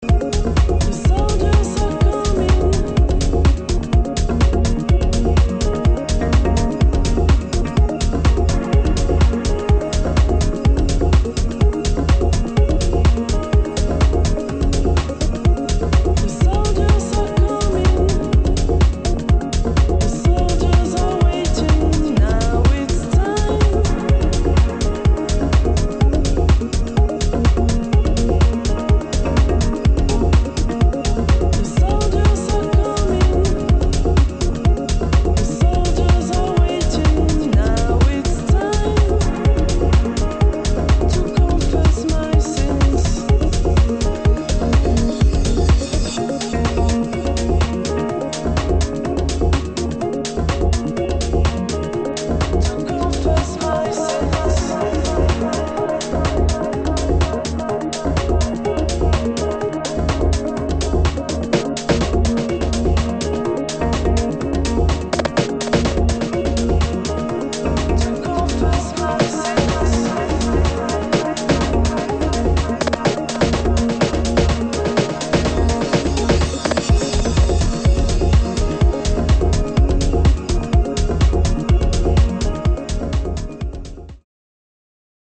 [ HOUSE / DEEP HOUSE ]